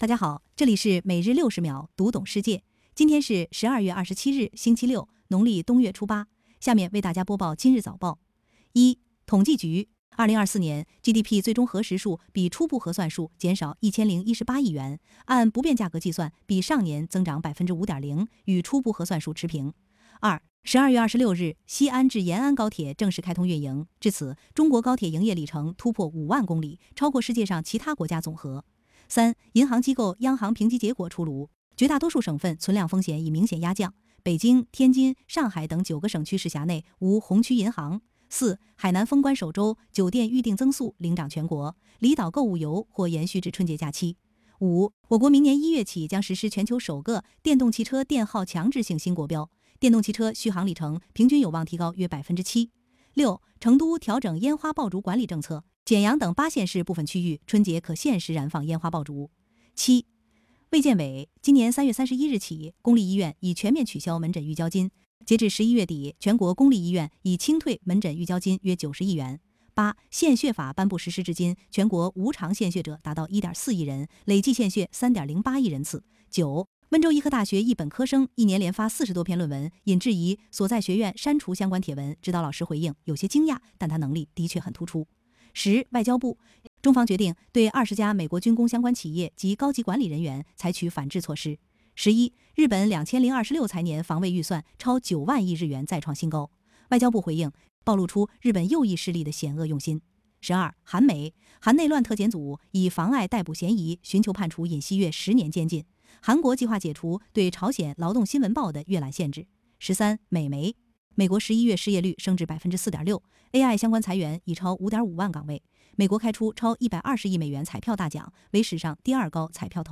本站从今天起，正式加入语音播报[f=aixin]